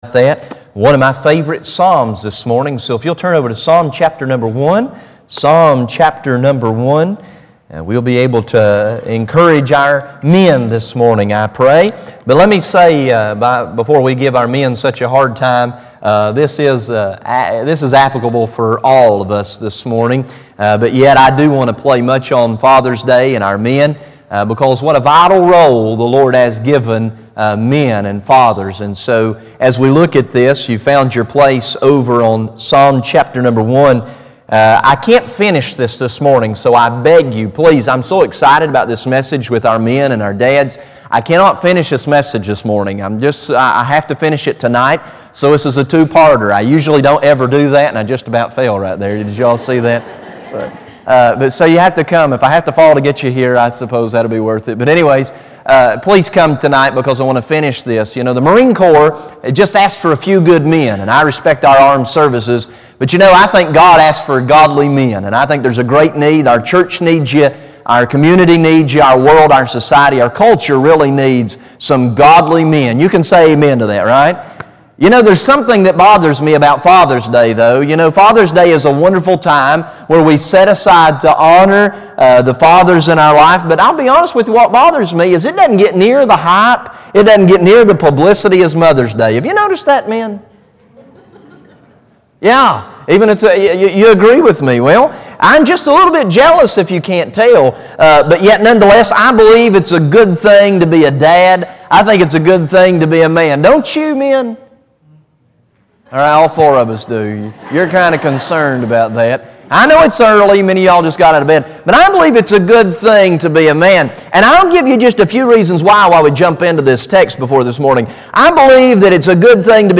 June 2011 Sermon Library